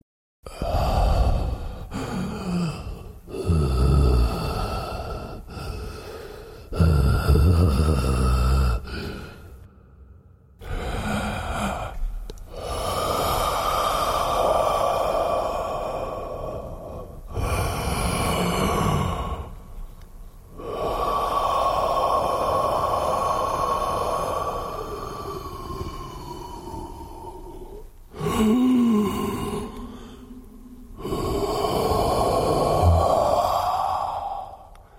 Тяжелое дыхание - Heavy Breathe
Отличного качества, без посторонних шумов.
155_heavy-breathe.mp3